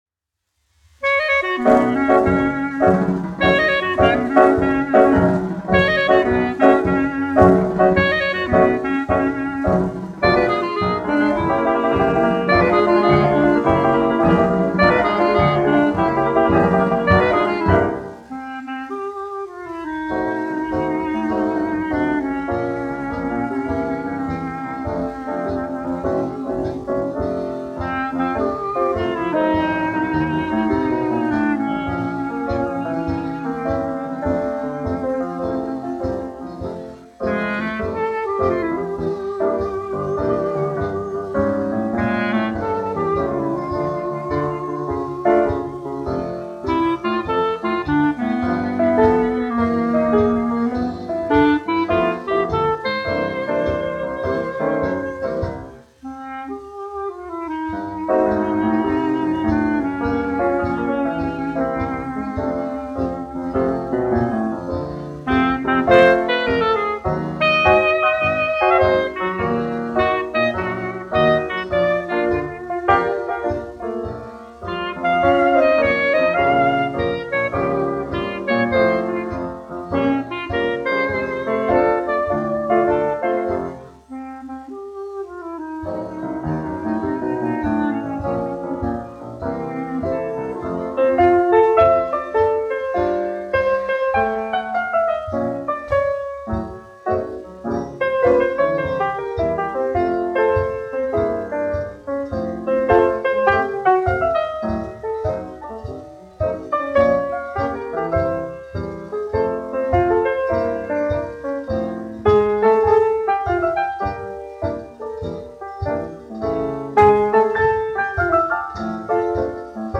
1 skpl. : analogs, 78 apgr/min, mono ; 25 cm
Fokstroti
Populārā instrumentālā mūzika
Skaņuplate